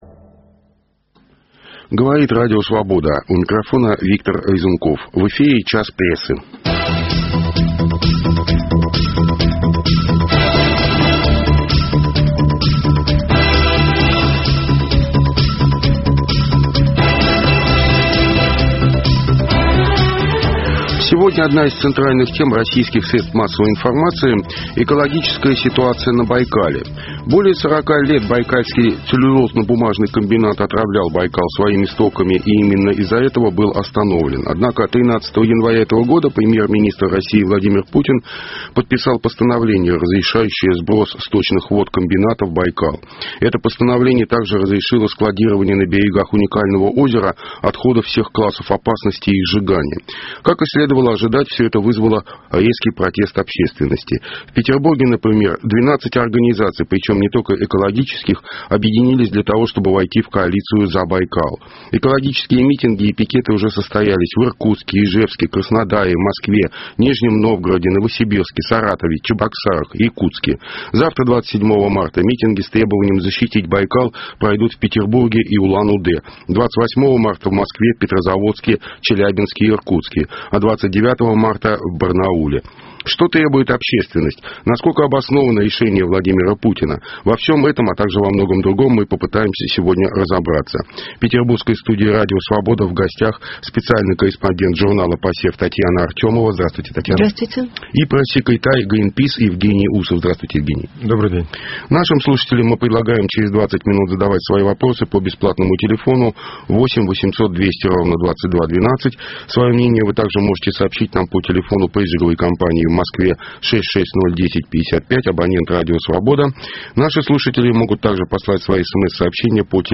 Против чего опять протестуют петербургские экологи и какое отношение к их протестам имеет озеро Байкал? Дискутируют